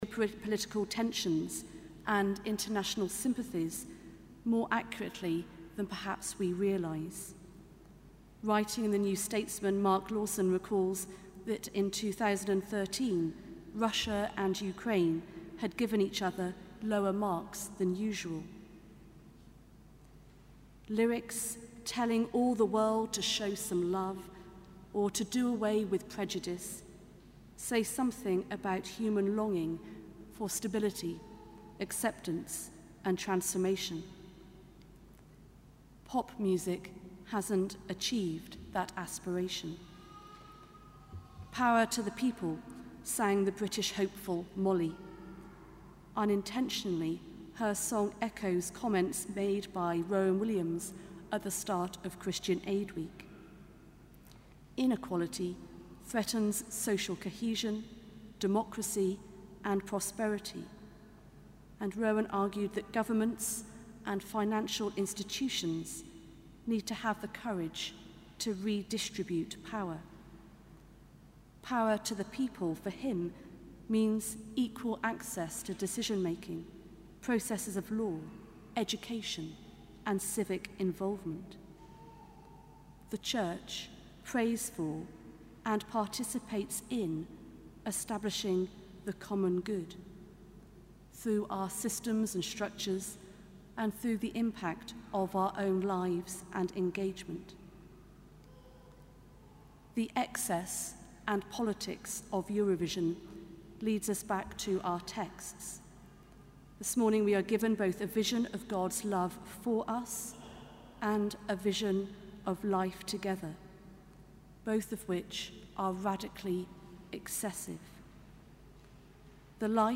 Sermon: Cathedral Eucharist 11 May 2014